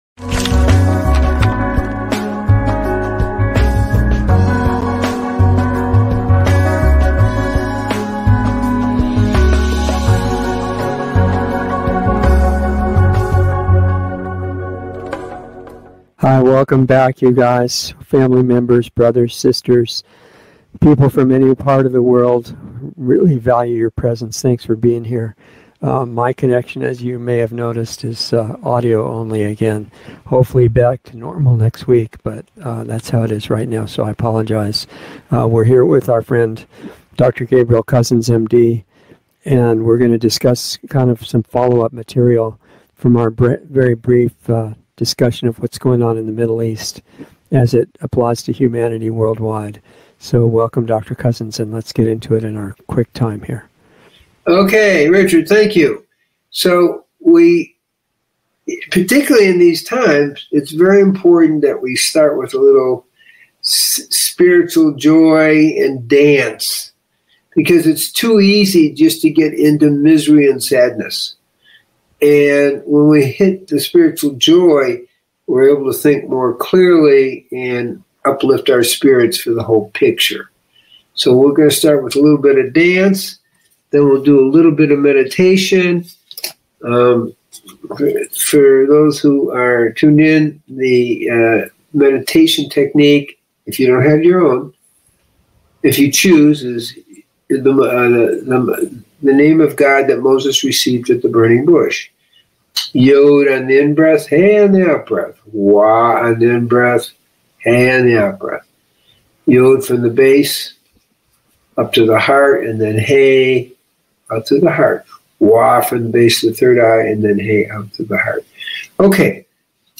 A new LIVE series